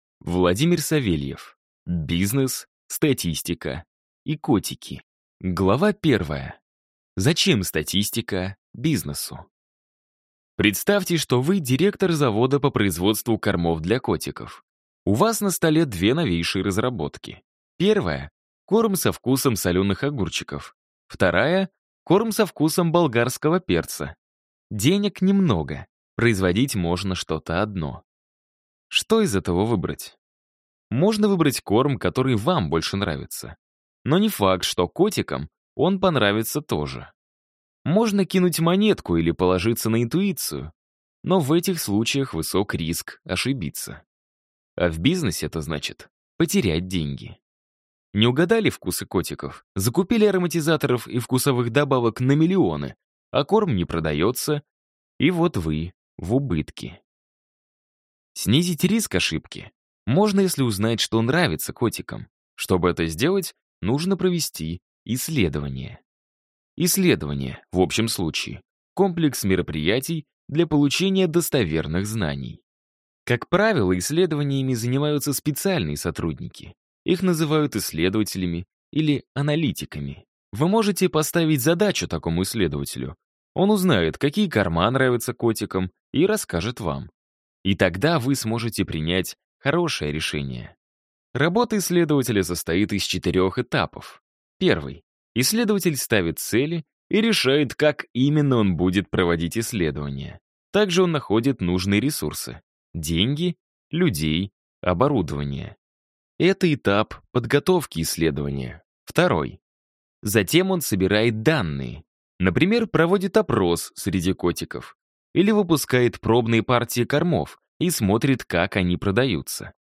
Аудиокнига Бизнес, статистика и котики | Библиотека аудиокниг